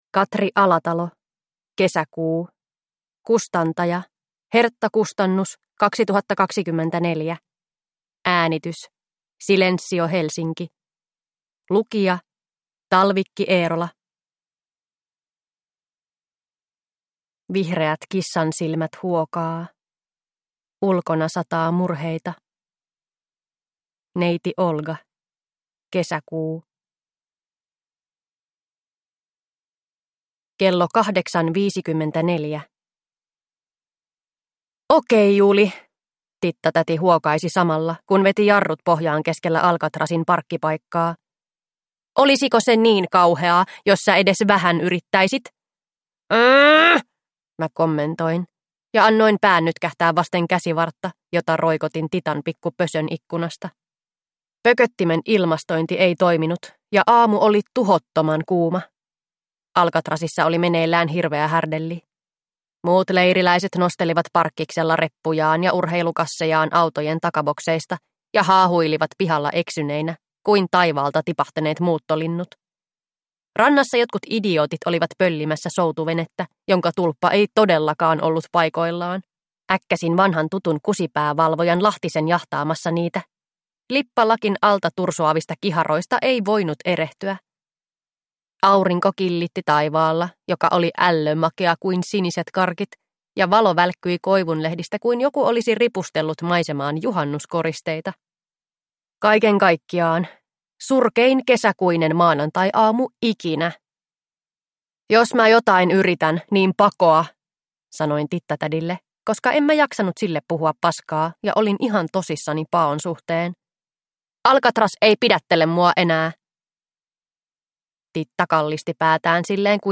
Kesäkuu – Ljudbok